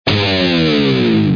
选错数字音效.mp3